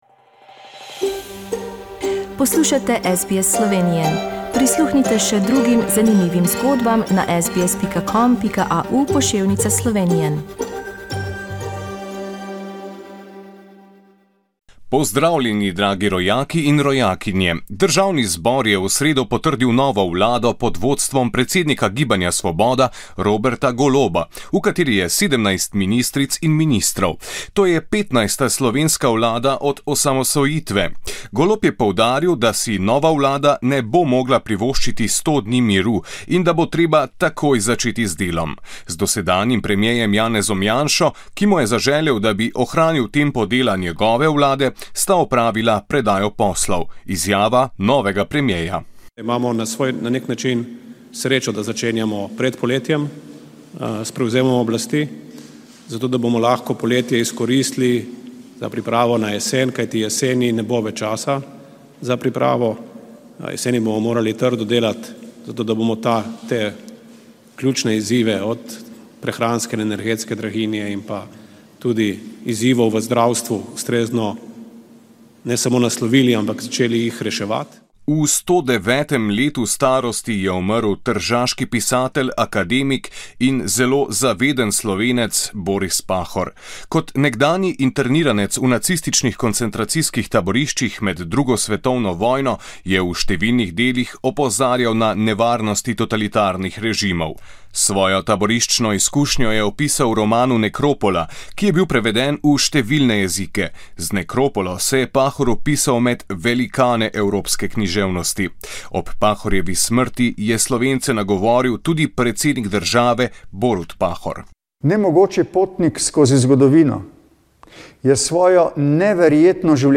Novice iz Slovenije 4.junija